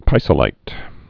(pīsə-līt)